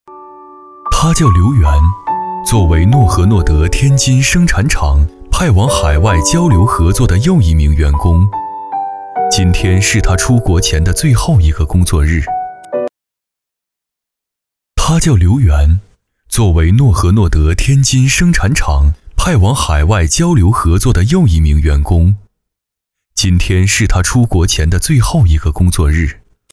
男654-人物解说 - 讲述感
男654大气浑厚 654